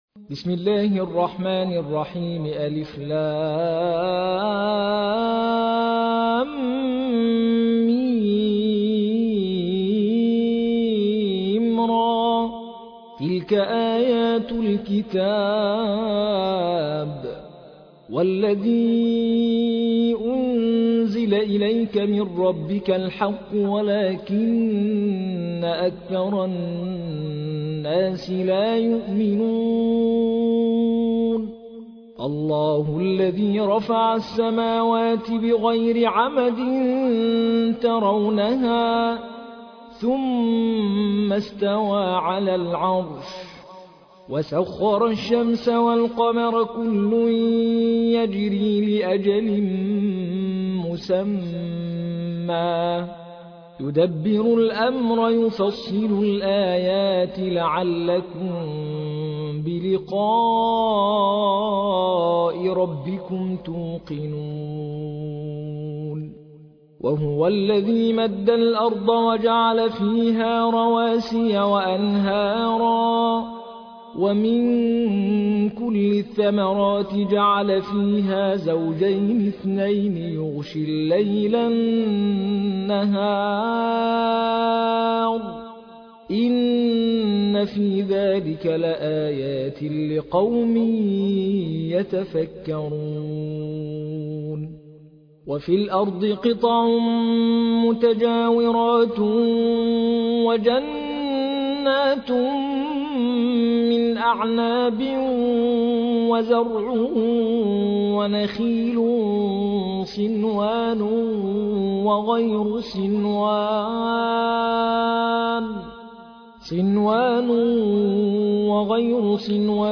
المصحف المرتل - حفص عن عاصم - Ar-Ra'd ( The Thunder )